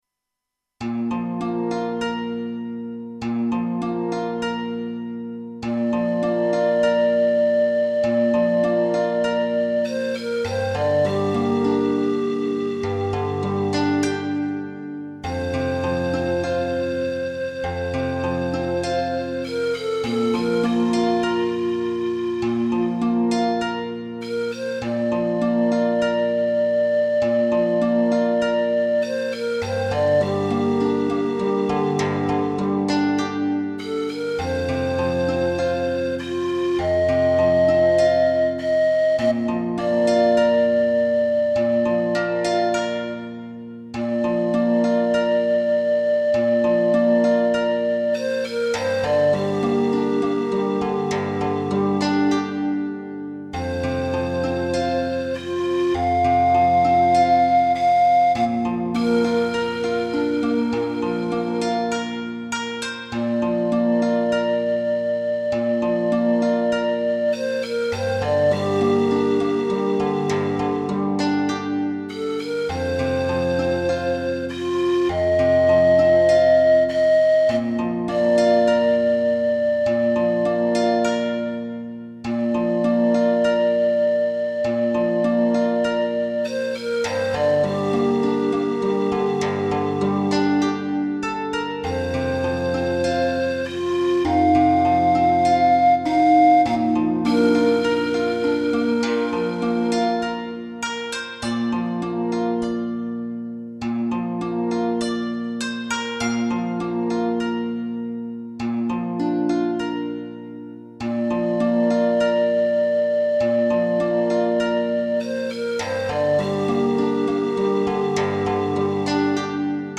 PanfluteとZheng Harpでオーディオ的に有意義にしました。
アイリッシュフルートとハープでの即興曲です。